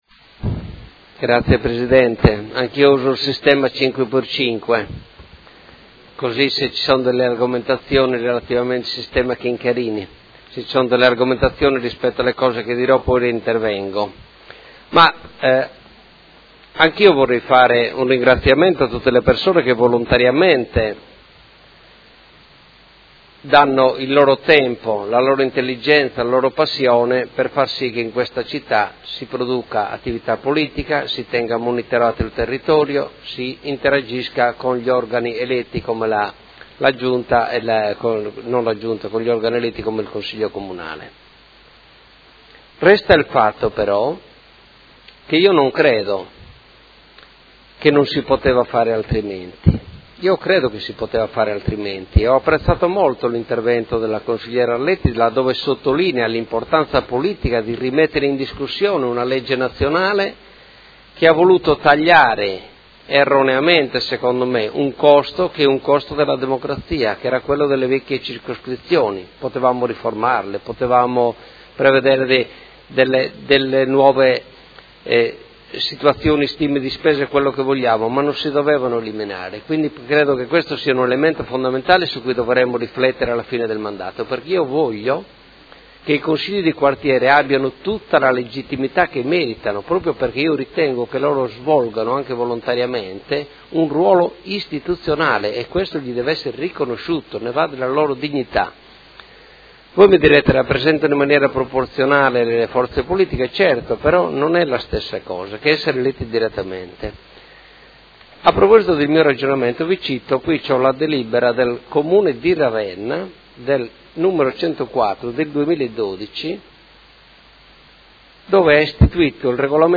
Seduta del 30/05/2016. Situazione dei Quartieri a Modena – dibattito